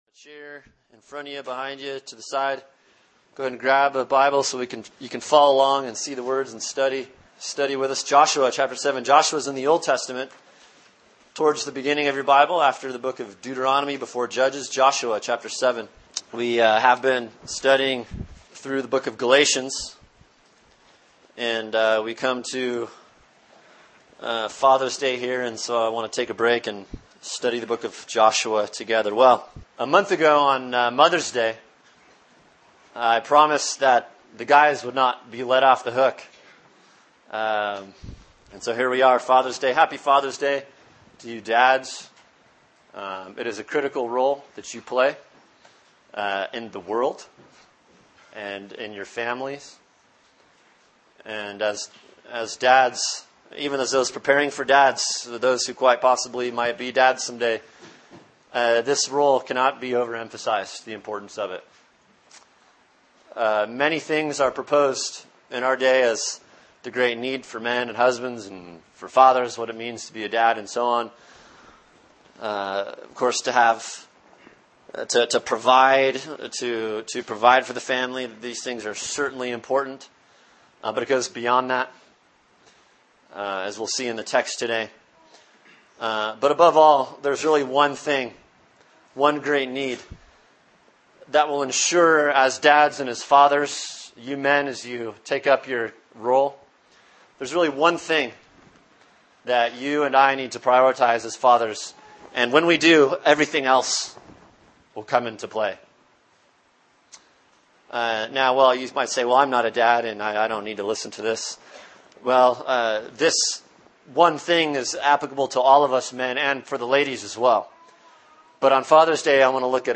Sermon: Joshua 7 “A Man and His God” | Cornerstone Church - Jackson Hole